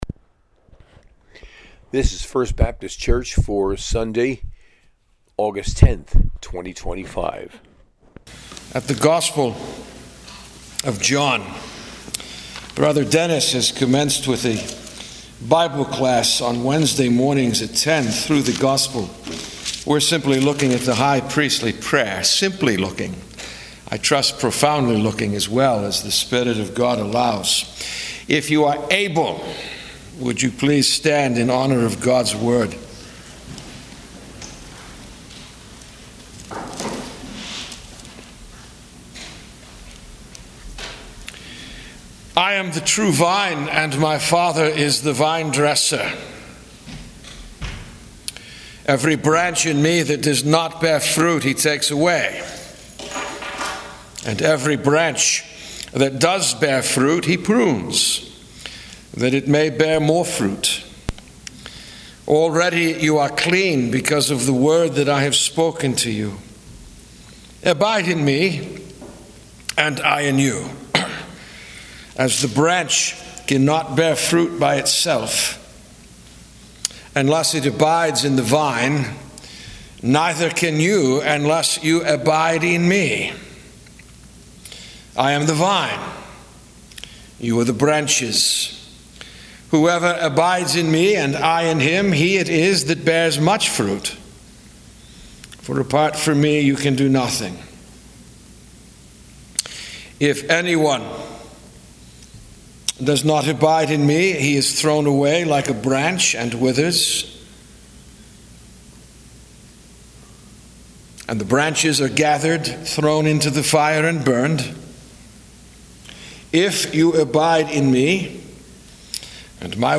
Sunday Sermon, taken from Gospel of John 15:1-11